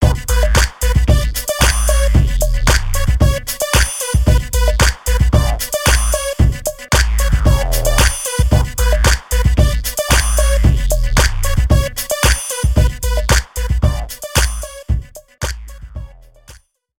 Lastly, Timbo would usually fill up the bass end with an interesting filtered bass line.
Running the whole mix through a subtle mastering chain of eq and compression will fatten out the sound and make the whole mix gel together. Here is the mastered version of our boil-in-a-bag Timbaland Style Club Banga!